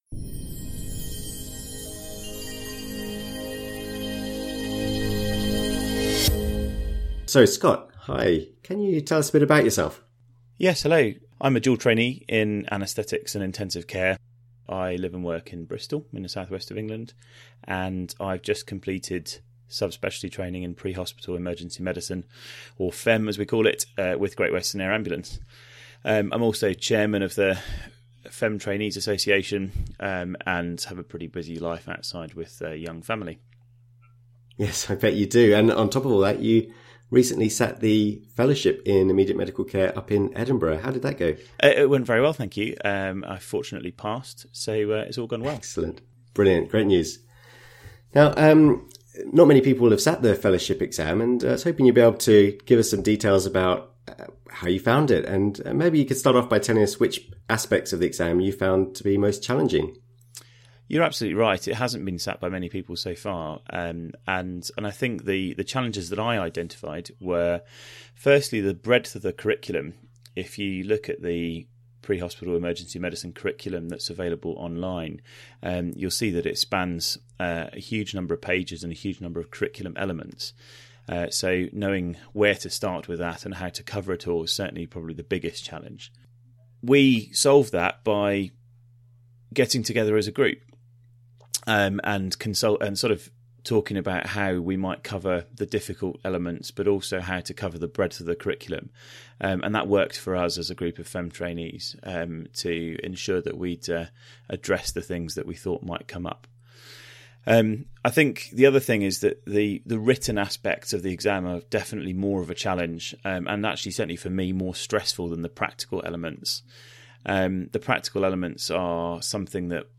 Interview with an FIMC Graduate